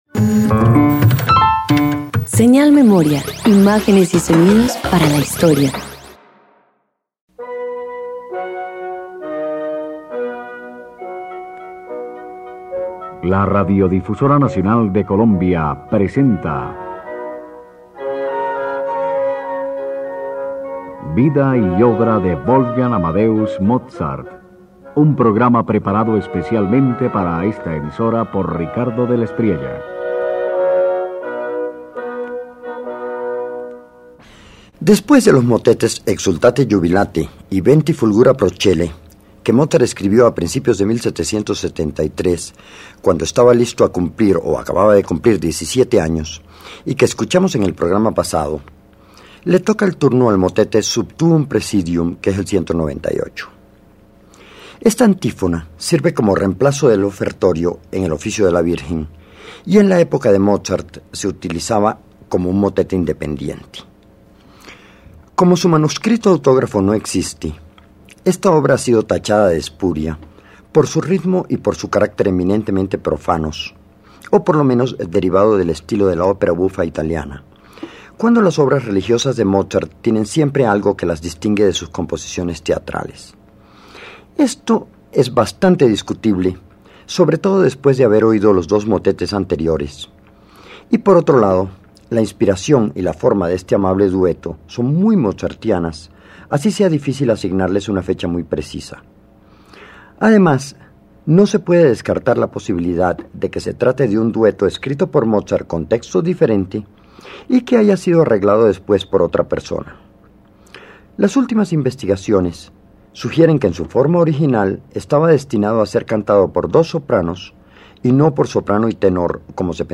Wolfgang Amadeus Mozart presenta el motete Sub Tuum Praesidium K198, una obra de carácter devocional influida por el estilo de la ópera bufa italiana. Atribuida a su juventud, esta obra destaca por su sencillez melódica y gracia vocal.
Radio colombiana